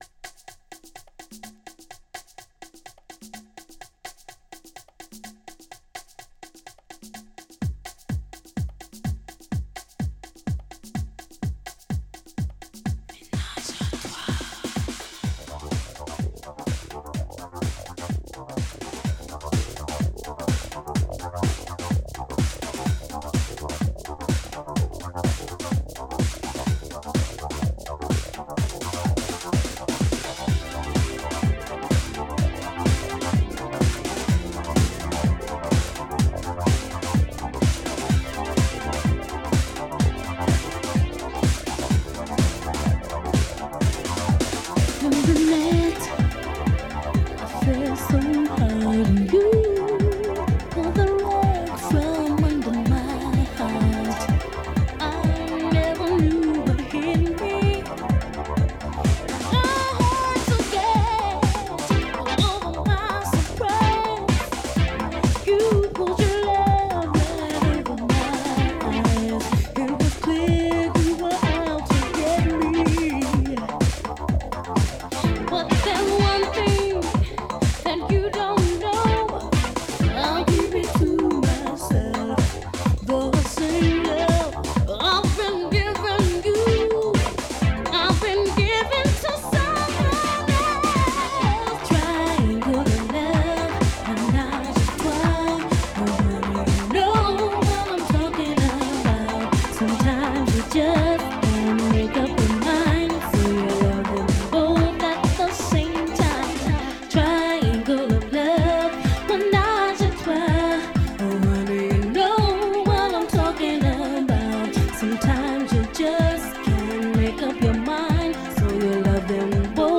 A classic Chicago house mix